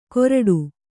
♪ koraḍu